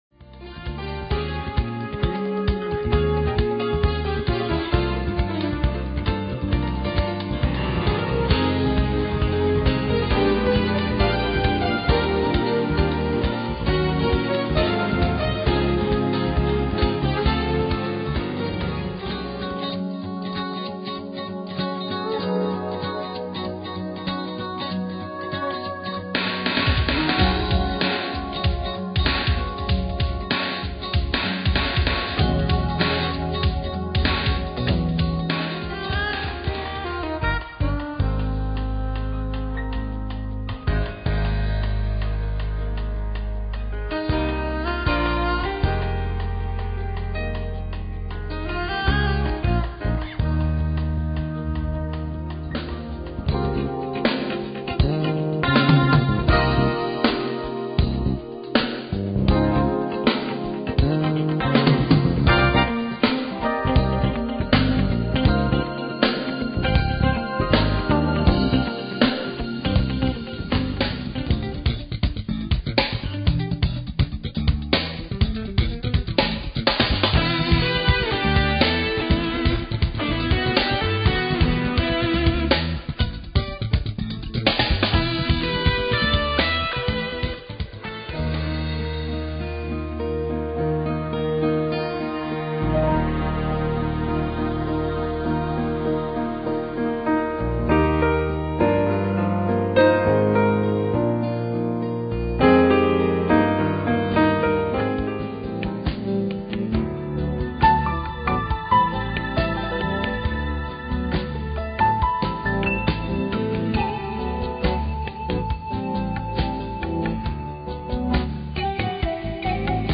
upscale, contemporary music